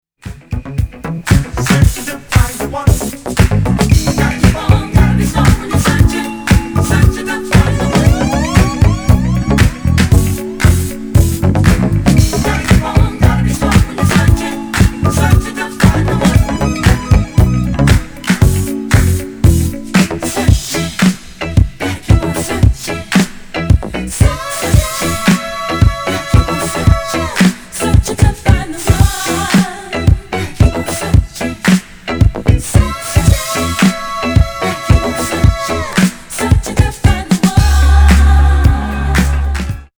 黒く弾むボトム・ラインにクラップ、スペイシーなシンセが絡む大名作！！
後半のヴァージョンで踊らずにはいられまヘン。